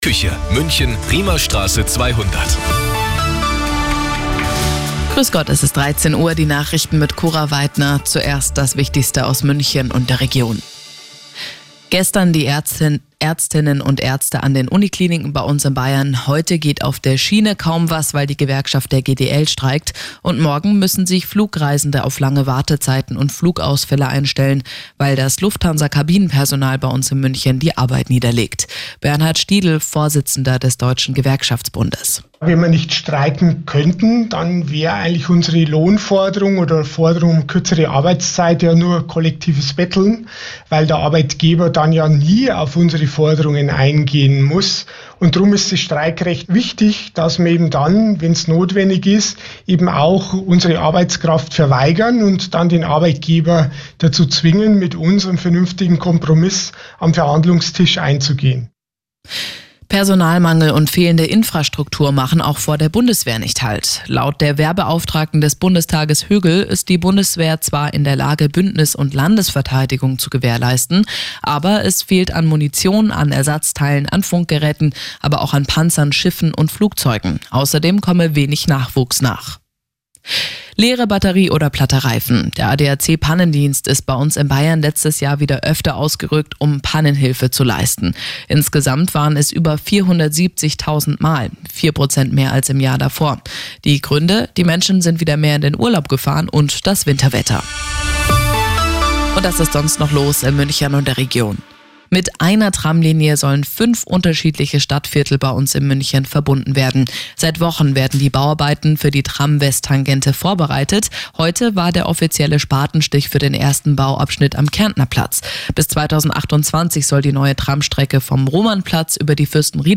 Die Arabella Nachrichten vom Dienstag, 12.03.2024 um 17:36 Uhr - 12.03.2024